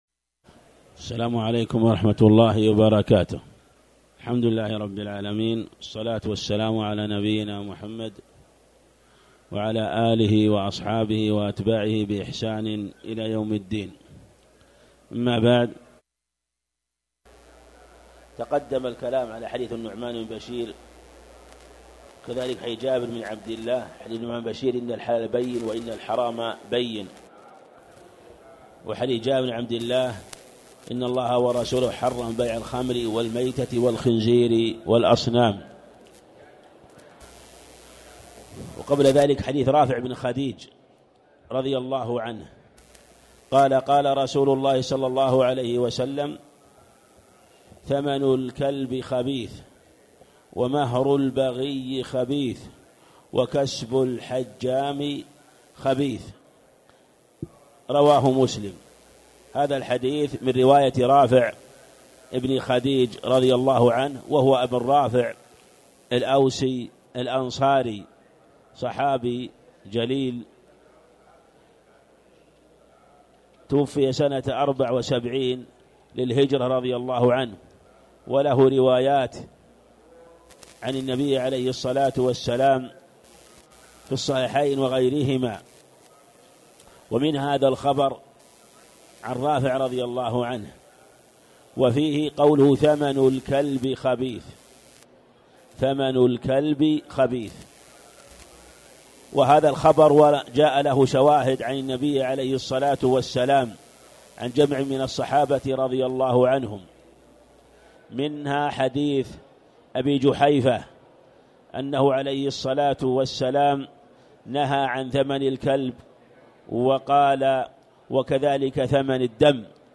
تاريخ النشر ٢ رمضان ١٤٣٨ هـ المكان: المسجد الحرام الشيخ